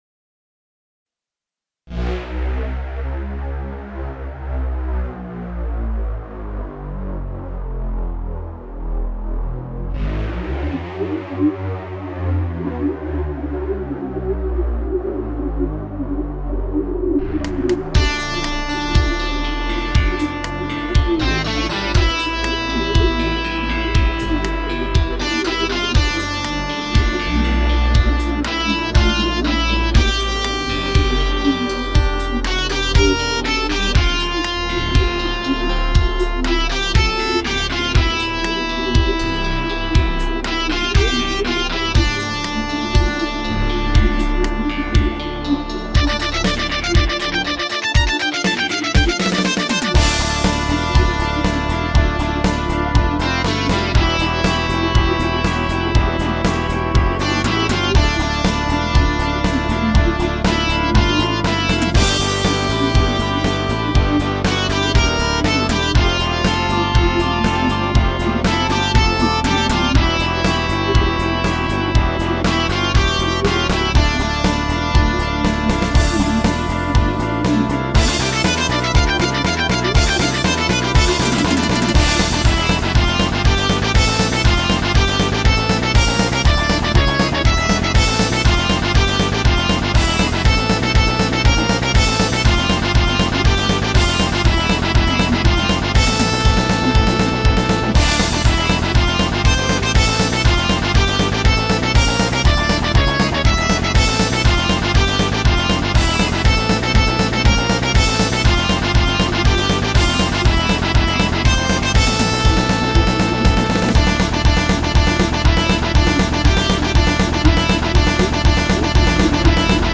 PROGRESSIVE ROCK MUSIC